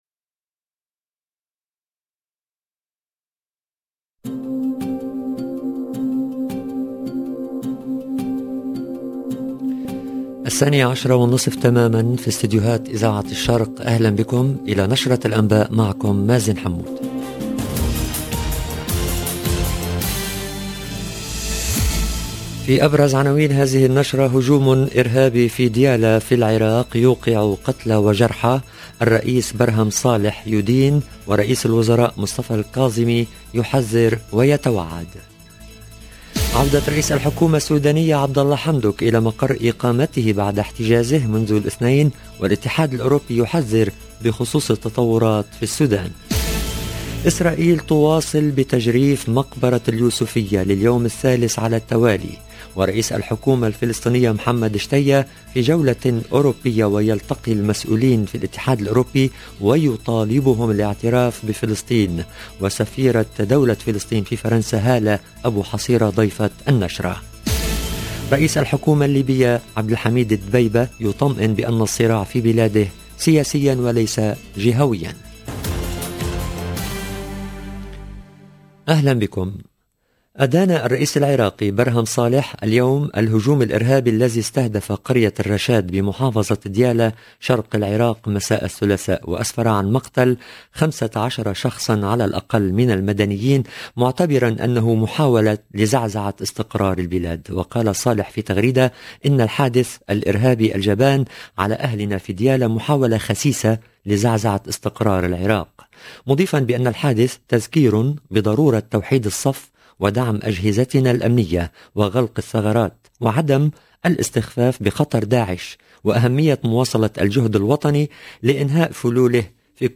EDITION DU JOURNAL DE 12H30 EN LANGUE ARABE